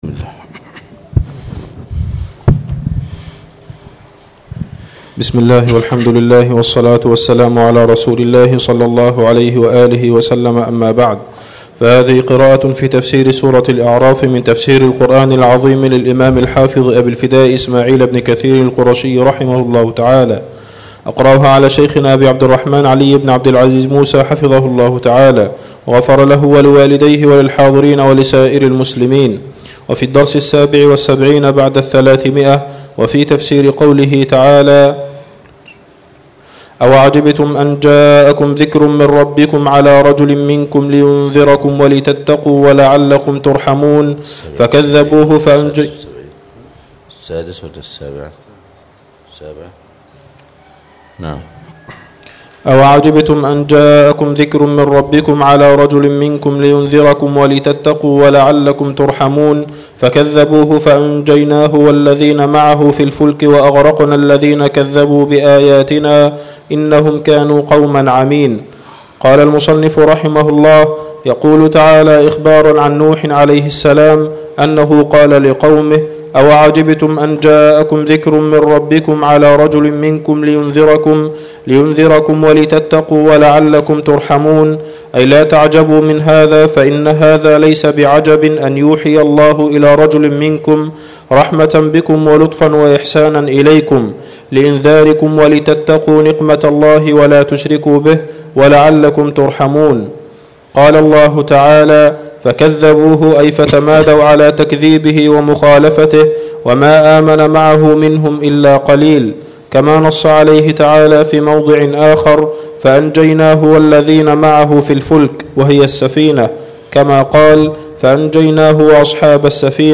الدرس السابع والسبعون بعد الثلاثمئه